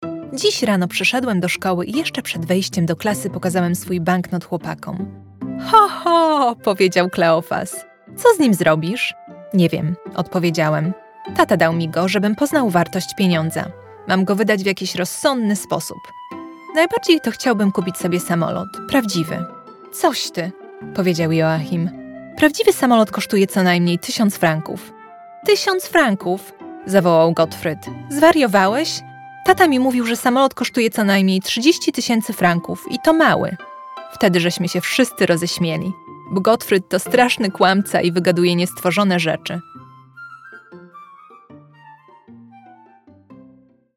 Livres audio
RODE NT1A, cabine vocale entièrement isolée dans un placard. Interface Scarlett 2i2, MacBook Air, Adobe Audition
Mezzo-soprano